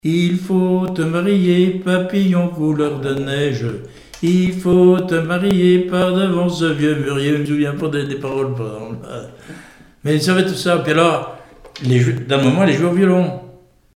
Mémoires et Patrimoines vivants - RADdO est une base de données d'archives iconographiques et sonores.
Témoignages et musiques
Pièce musicale inédite